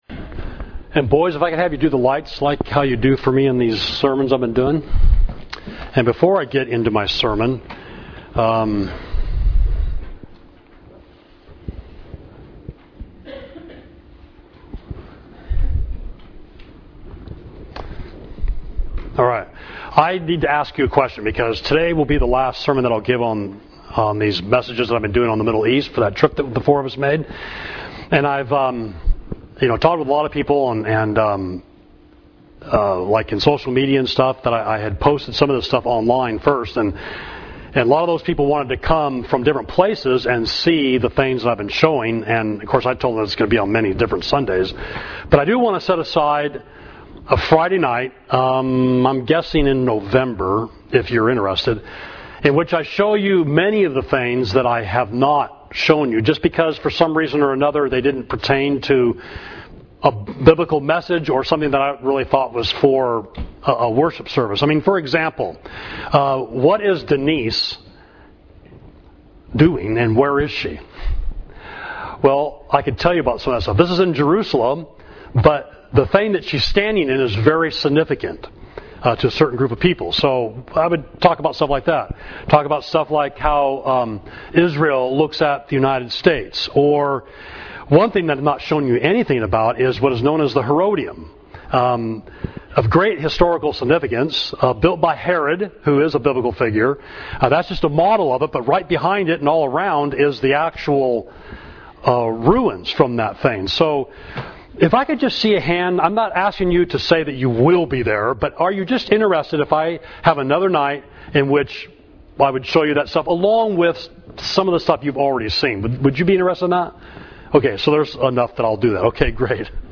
Sermon: Egypt Part 3